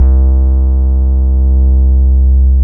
29SYN.BASS.wav